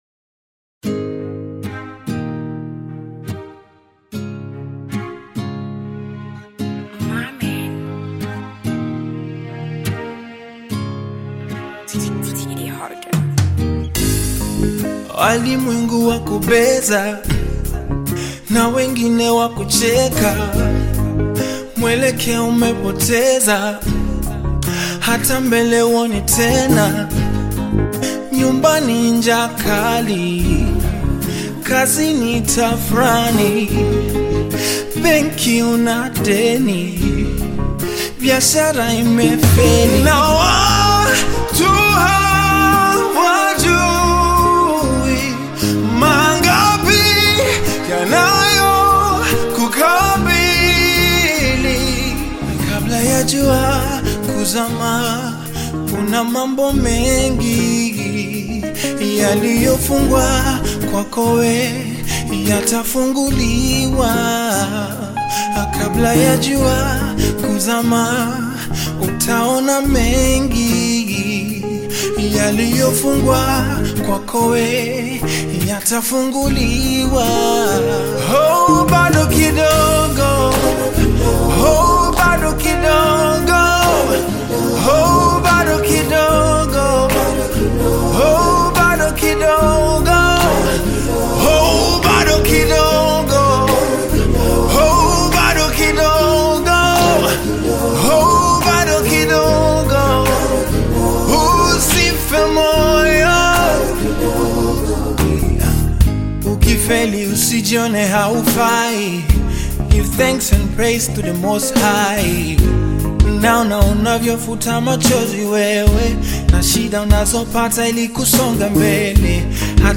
AudioBongo flavaR&B
a classic Bongo Flava/R&B anthem